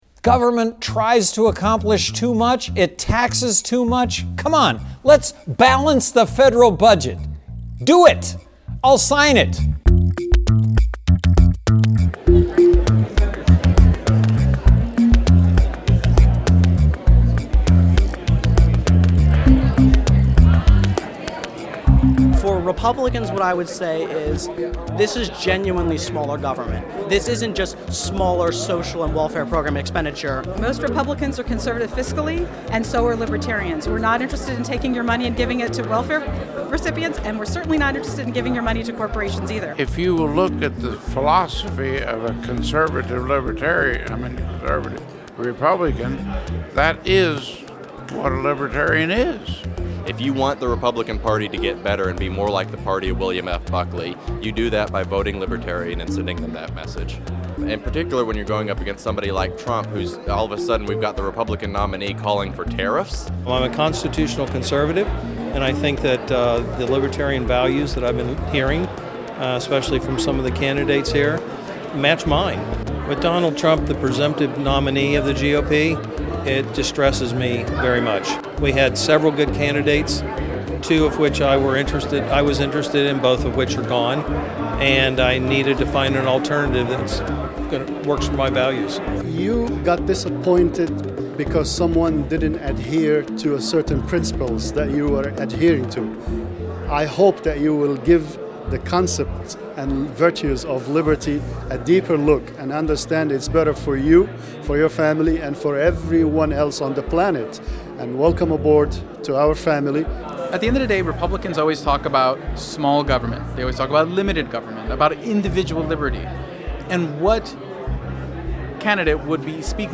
Reason TV asked presidential candidates and members of the Libertarian Party at the Libertarian National Convention in Orlando, Florida why Republicans should abandon Donald Trump and vote Libertarian this year.